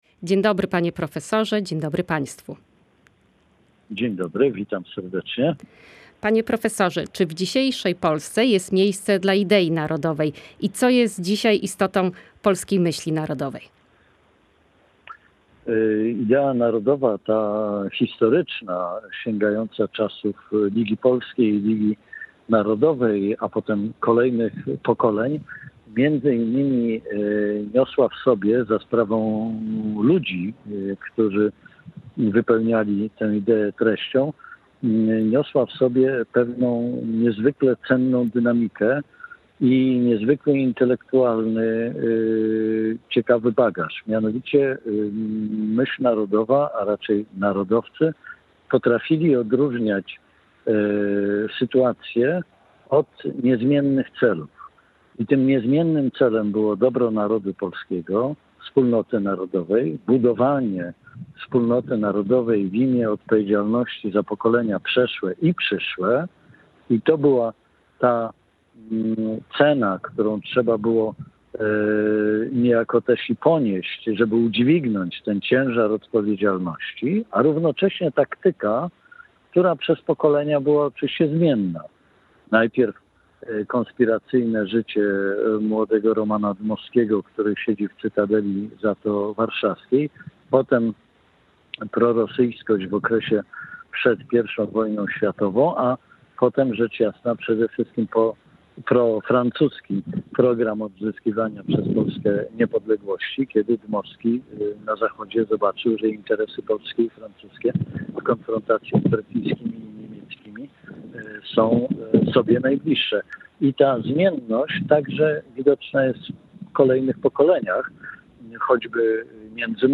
Czy część wyborców Konfederacji będzie skłonna poprzeć w wyborach prezydenta Andrzeja Dudę? O tym w audycji Gość Dnia Radia Gdańsk.